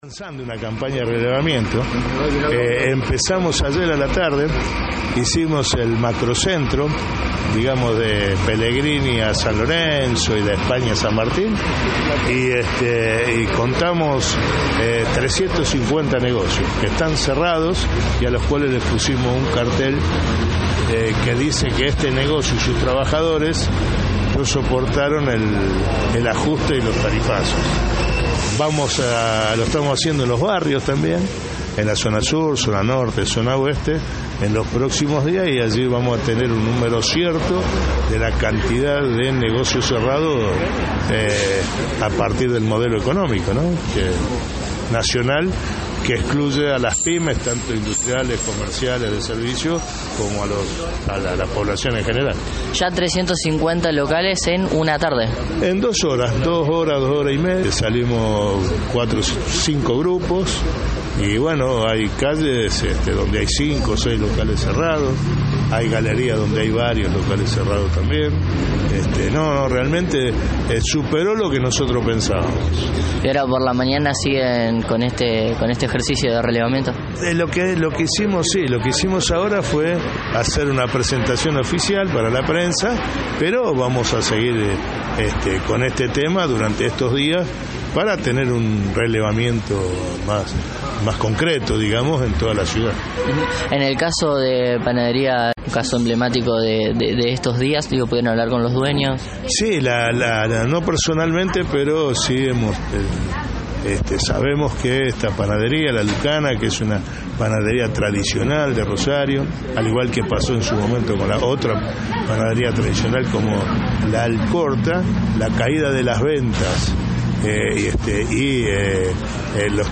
frente a la tradicional panadería Lucana que debió cerrar sus puertas.